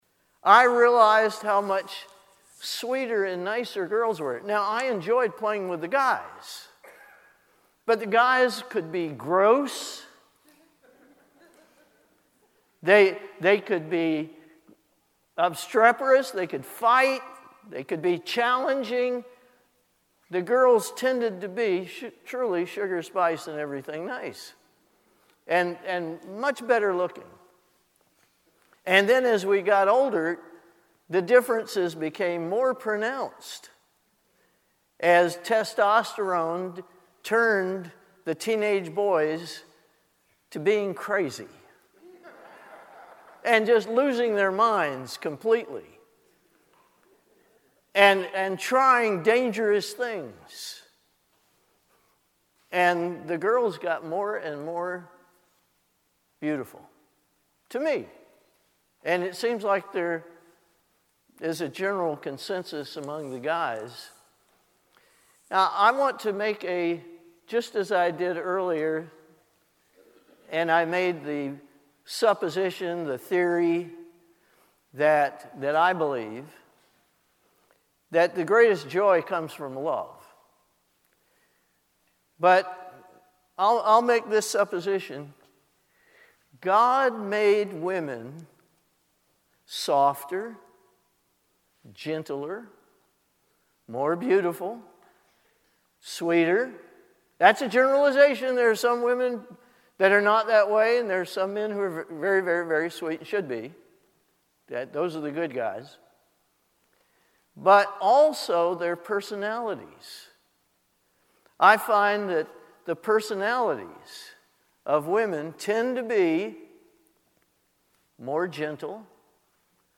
Due to a technical issue, the first 6 minutes of this message was not recorded.
This sermon was given at the Ocean City, Maryland 2022 Feast site.